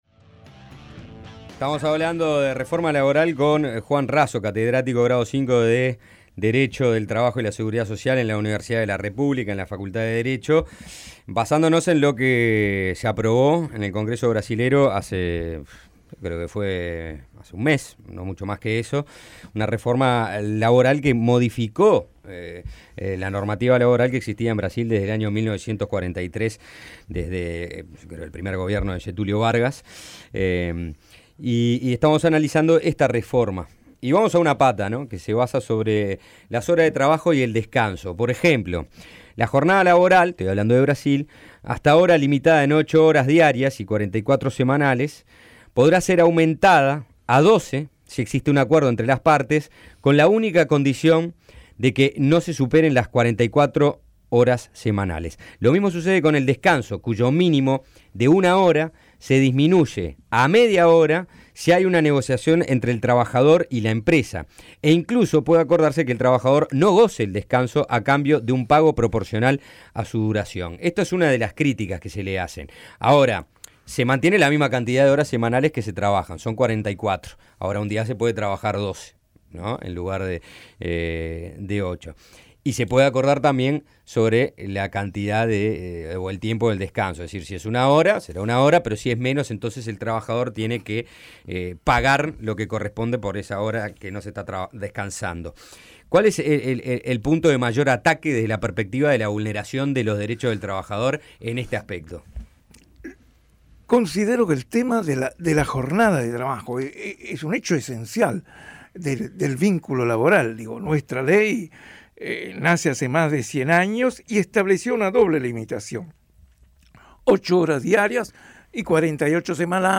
En entrevista con Suena Tremendo